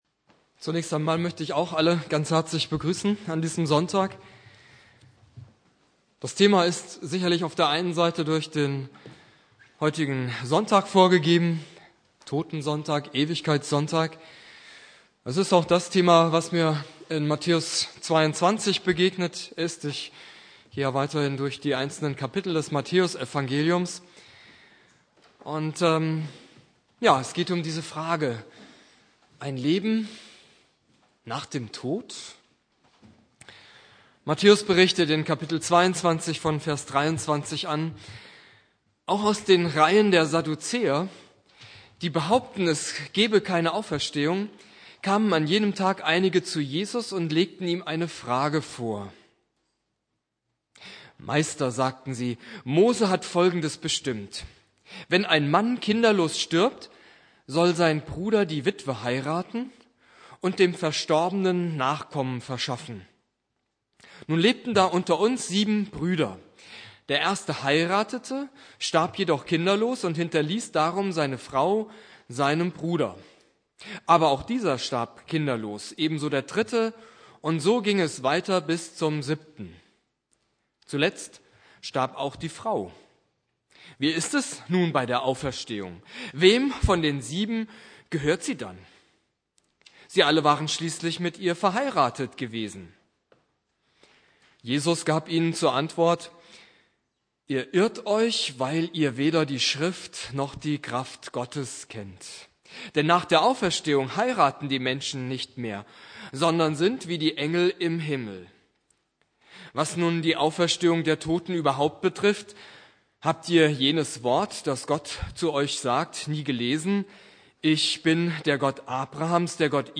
Predigt
Ewigkeitssonntag Prediger